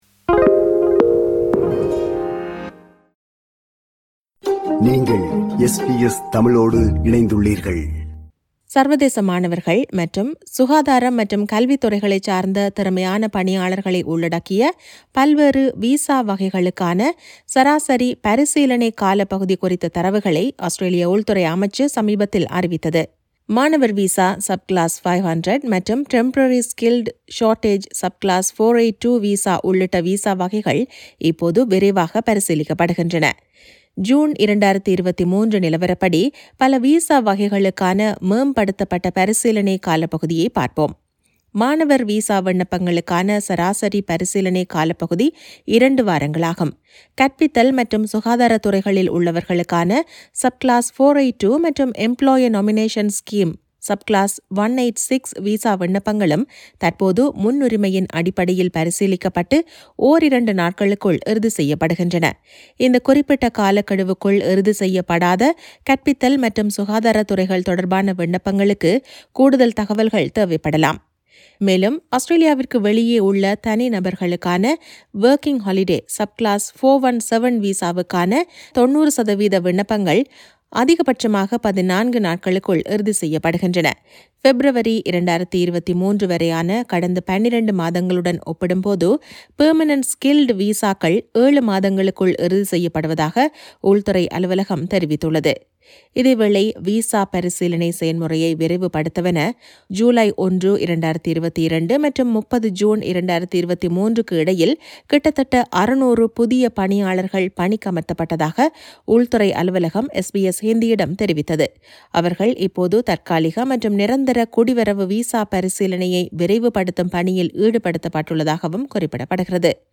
சர்வதேச மாணவர்கள், சுகாதாரம் மற்றும் கல்வித் துறைகளைச் சார்ந்த திறமையான பணியாளர்களை உள்ளடக்கிய பல்வேறு விசா வகைகளுக்கான சராசரி பரிசீலனை காலப்பகுதி குறித்த தரவுகளை ஆஸ்திரேலிய உள்துறை அமைச்சு சமீபத்தில் அறிவித்தது. இதுகுறித்த செய்தி விவரணத்தை